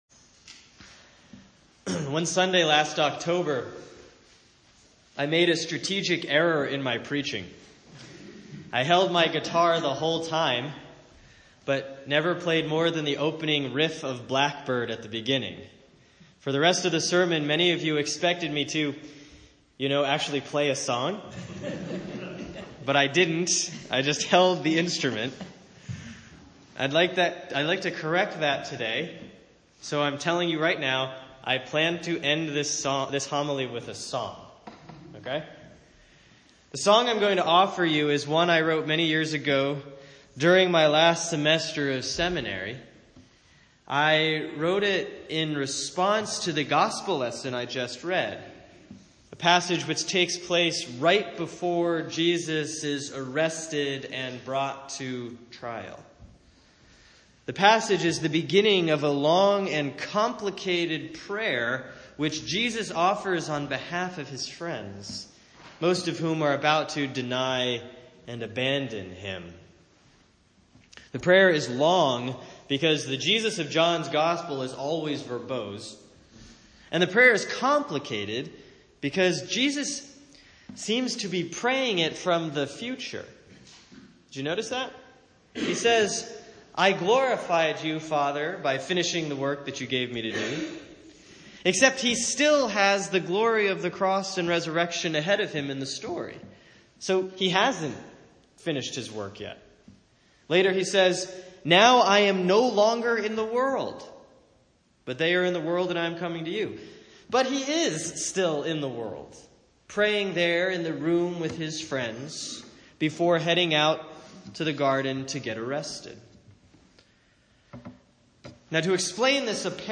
We've all experienced moments of eternity, which exposes the error in thinking eternal life doesn't start until after we die. This sermon ends with a song to remind us that Jesus invites us to join him right here, right now.